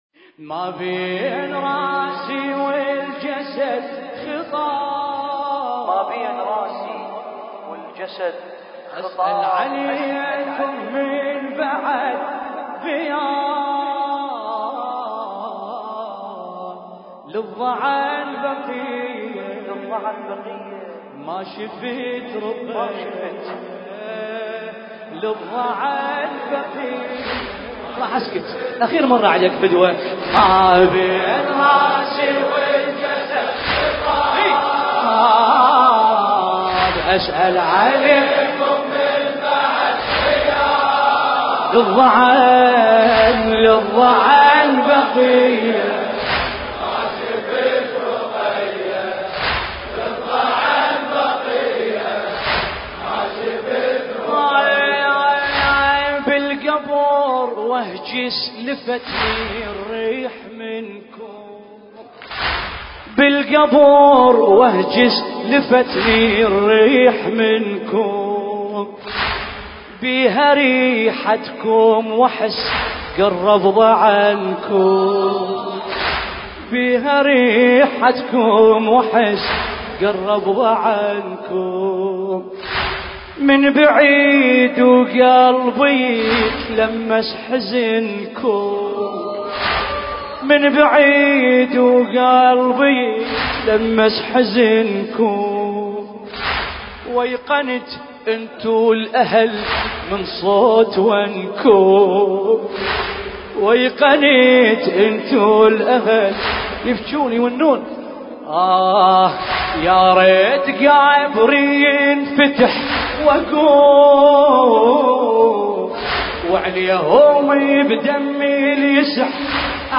المراثي
ليلة الأربعين حملة الديري فندق كريستال – كربلاء المقدسة